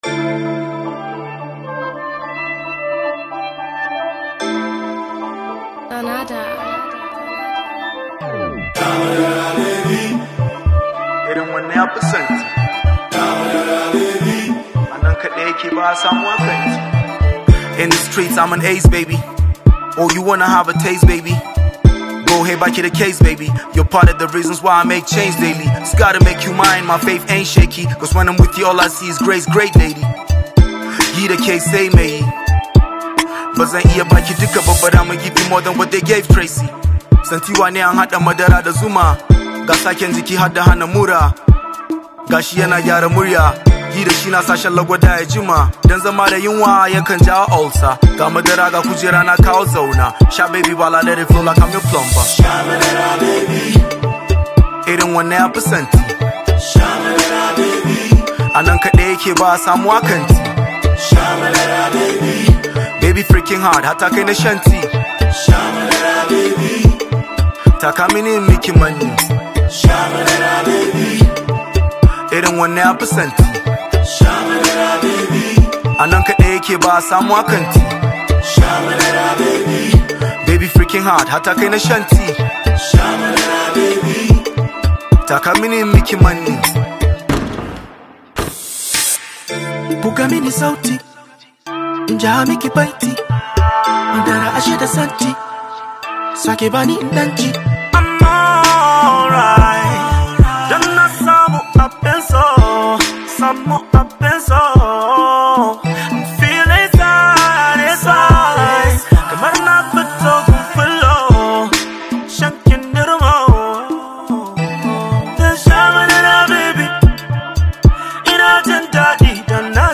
rap therapist